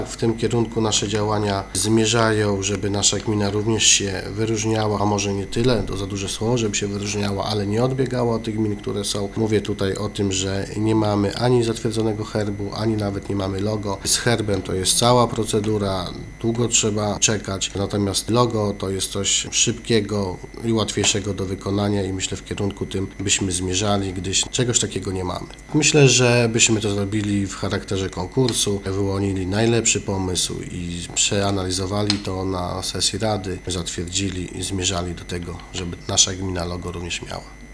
- Nasza gmina nie ma dotychczas znaku, który by jednoznacznie identyfikował ten obszar. Jest to potrzebne choćby dla promocji. Takie logo kojarzyłoby się z gminą jako, na przykład atrakcyjnymi terenami do turystyki i rekreacji - mówi wójt Starego Brusa Paweł Kołtun.